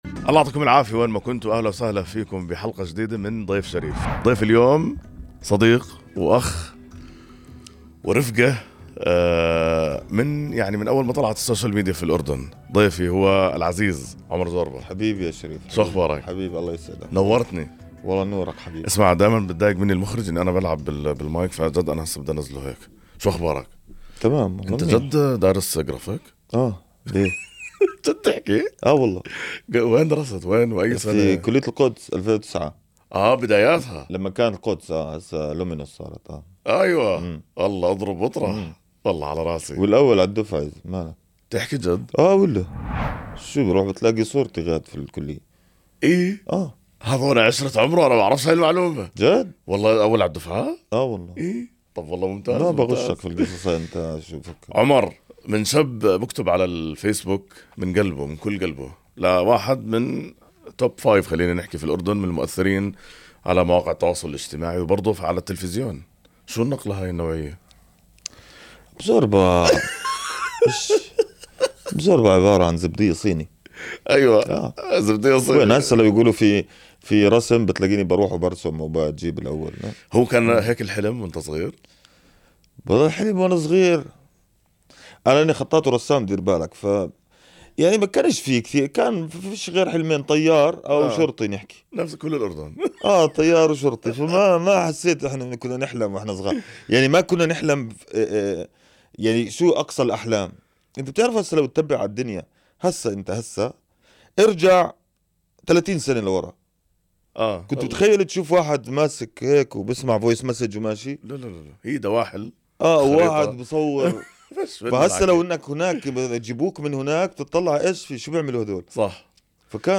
سنغوص معًا في حوارٍ ممتدّ بين الشغف والواقع، بدءًا من أيام الدراسة في كلية القدس (لومينوس) حين كان الأول على دفعته، مرورًا برحلته من كاتب ساخر على فيسبوك إلى أيقونةٍ في صناعة المحتوى.